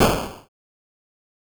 8bit Noise
8bit_FX_noise_01_02.wav